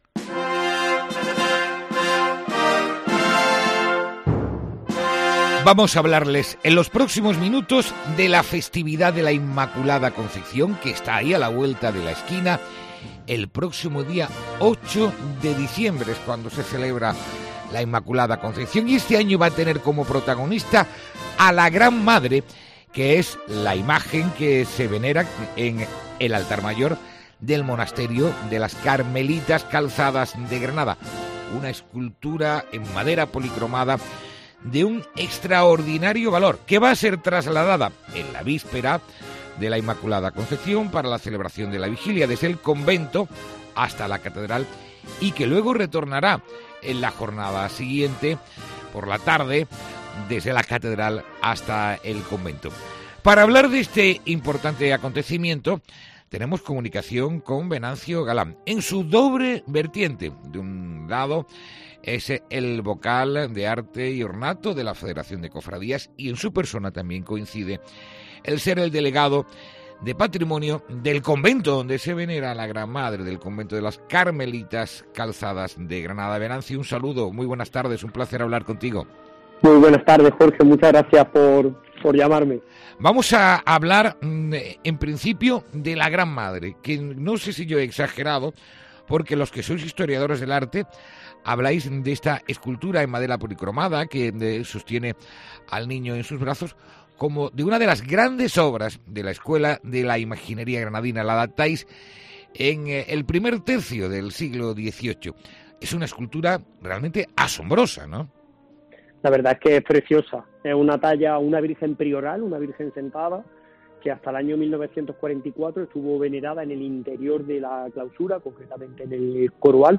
ENTREVISTA|| La Cofradía de la Virgen del Carmen puede resurgir